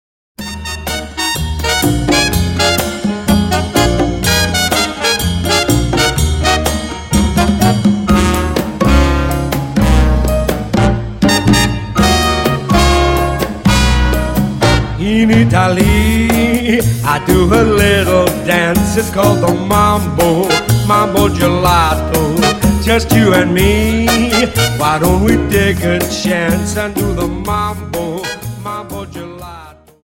Dance: Cha Cha Song